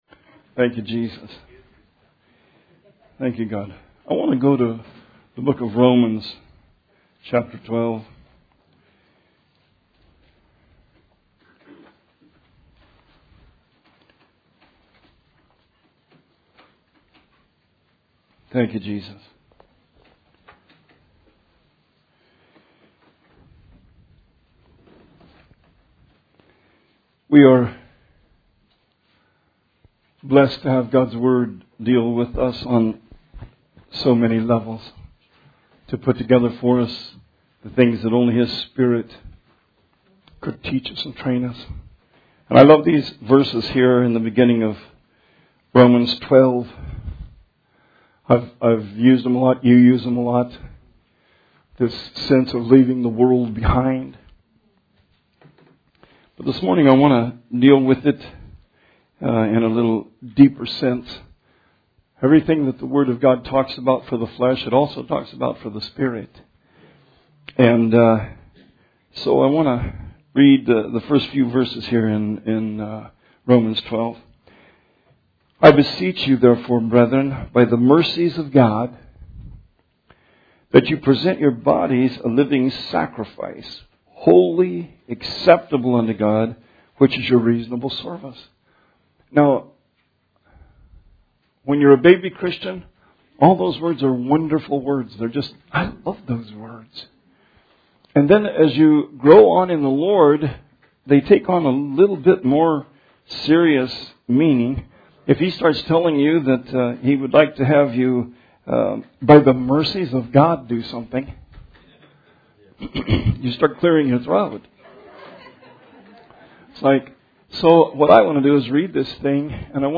Sermon 7/12/20